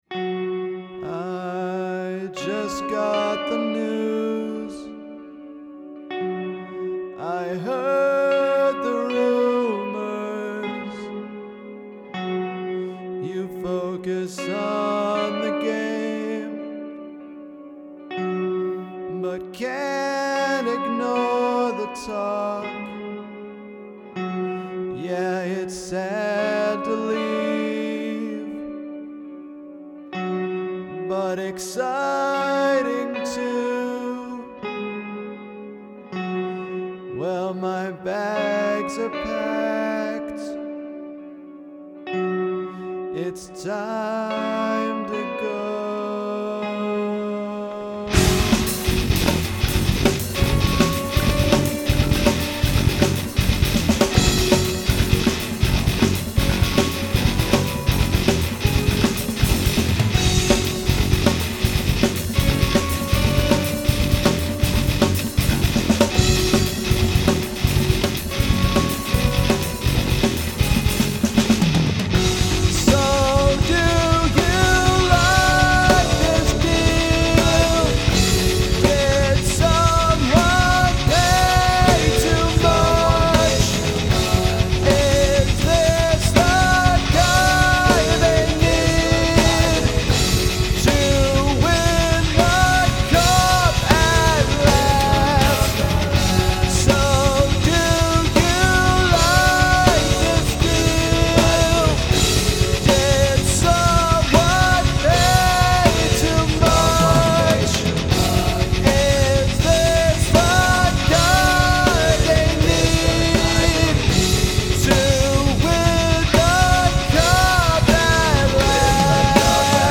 This is likely the djent-iest djent fest of a song I’ve ever done. Oddly enough, this almost feels like more of a showcase for the bass and drums to me. Also, I like how the rhythm guitars almost get lost in the last section because so much is going on…